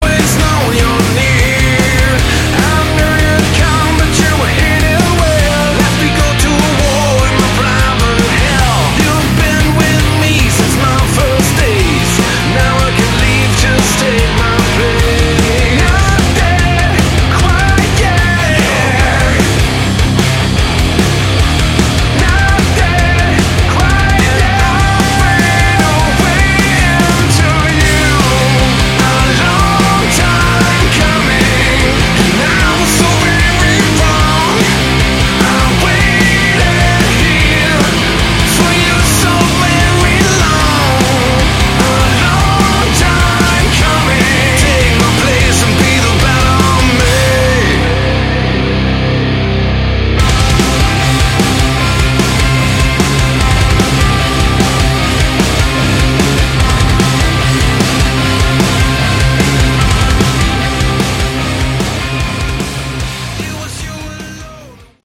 Category: Hard Rock
vocals
bass
drums
guitars